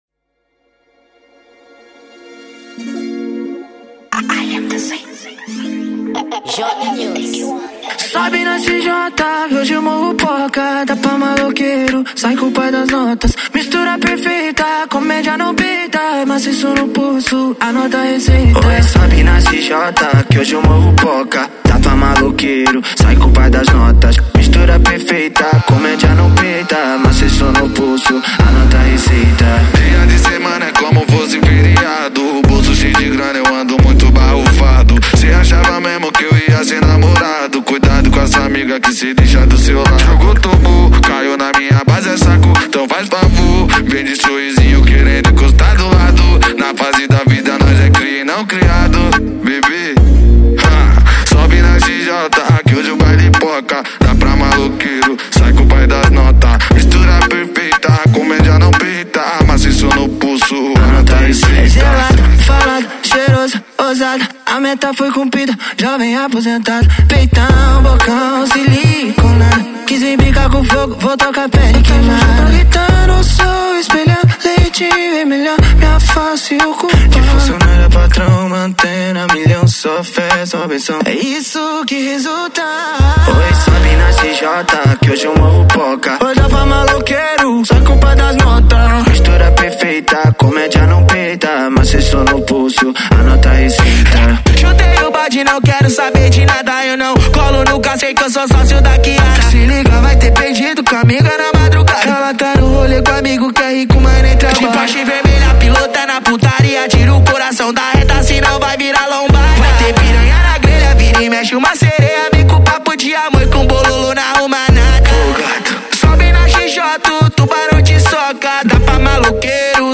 Gênero: Afro Fank